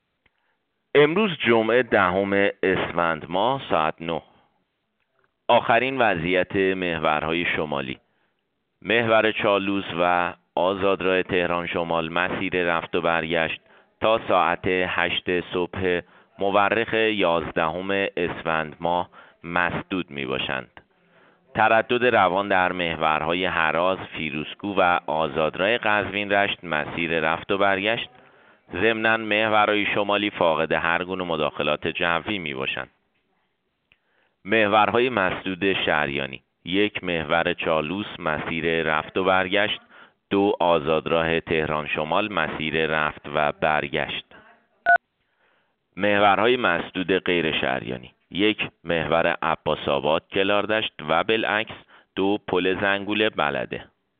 گزارش رادیو اینترنتی از آخرین وضعیت ترافیکی جاده‌ها ساعت۹ دهم اسفند؛